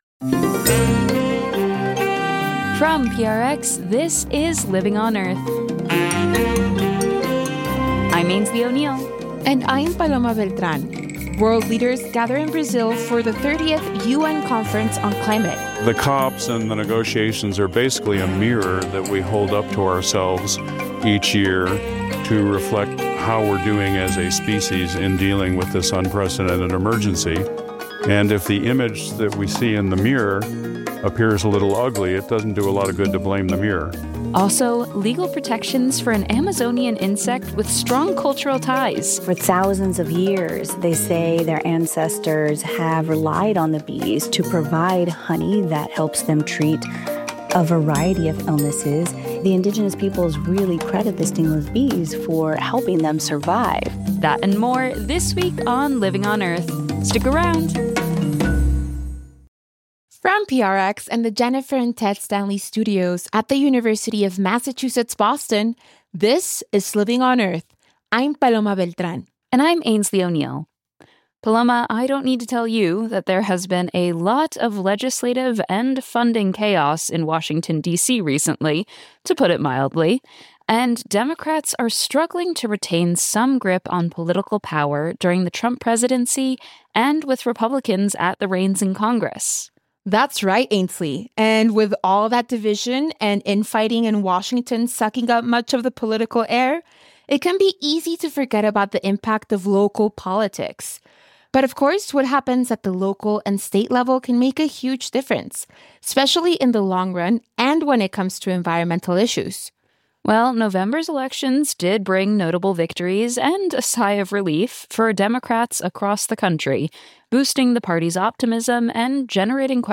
This week's Living on Earth, PRI's environmental news and information program.